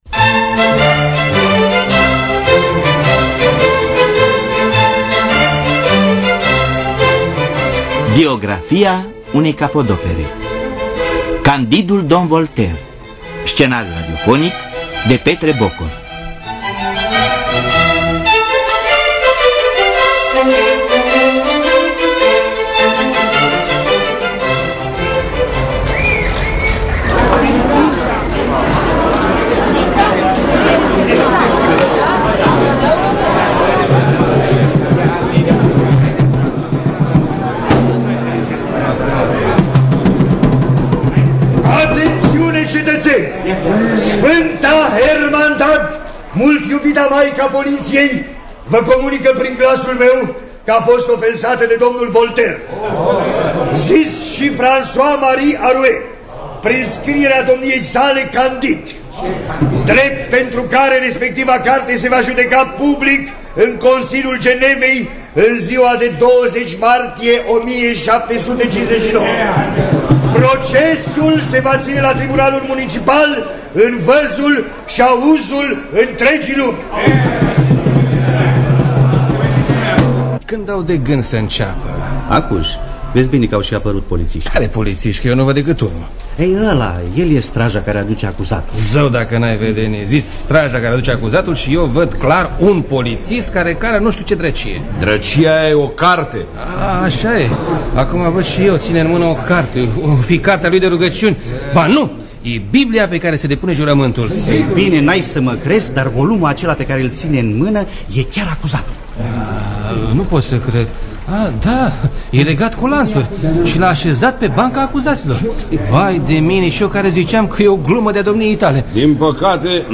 Scenariul radiofonic de Petre Bokor.